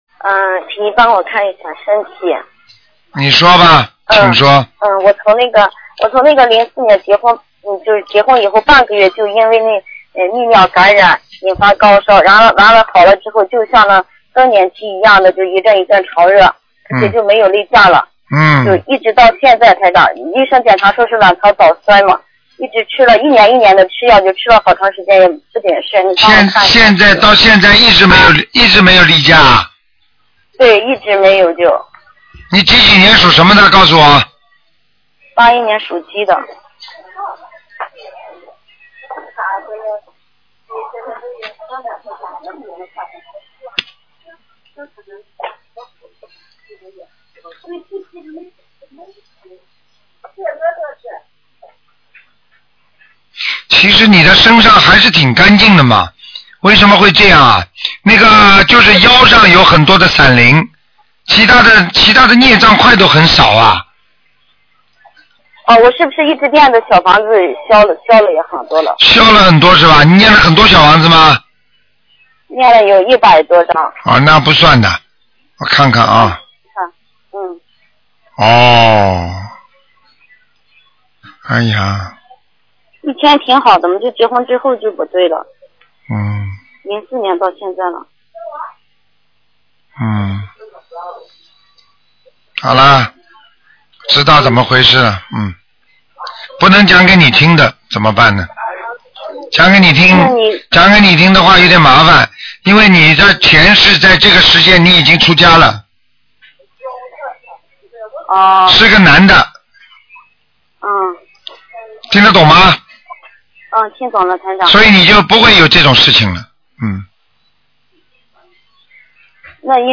目录：2013年01月_剪辑电台节目录音集锦